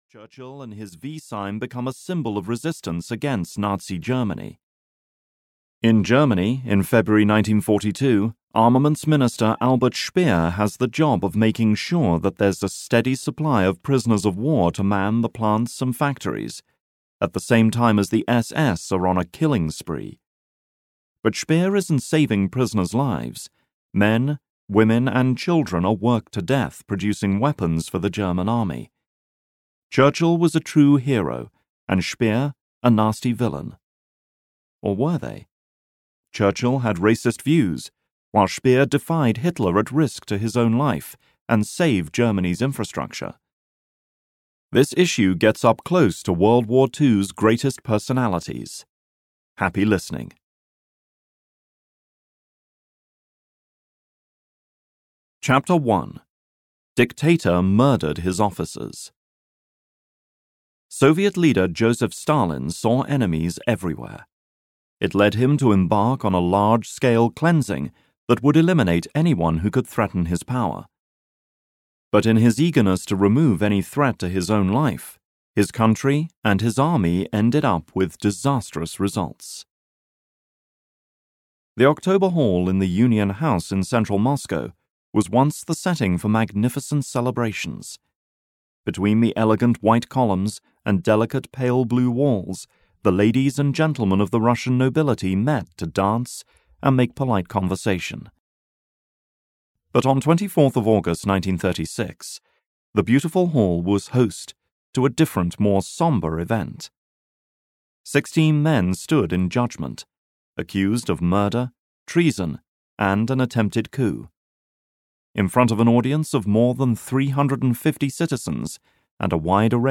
Heroes and Villains (EN) audiokniha
Ukázka z knihy